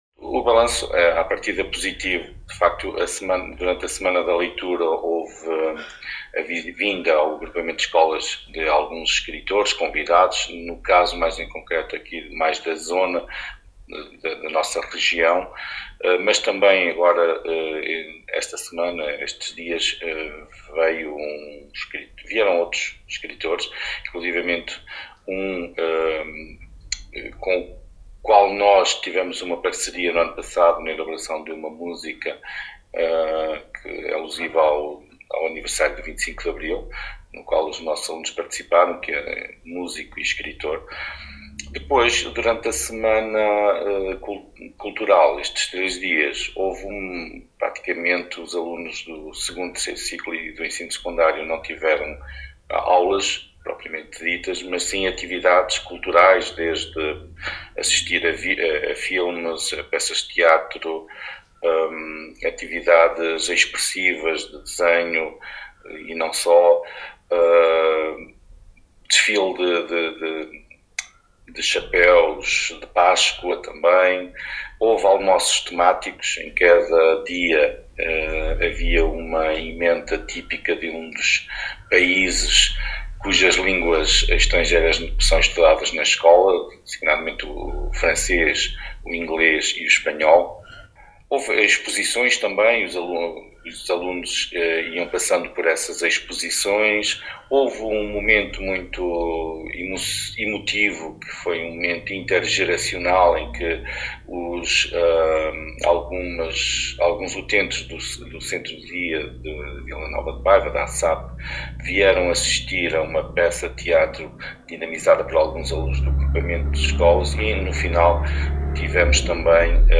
em declarações à Alive FM, fez um balanço positivo destas actividades.